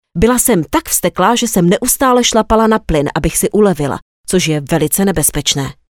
Native speaker Female 30-50 lat
Young female voice for all applications.
Nagranie lektorskie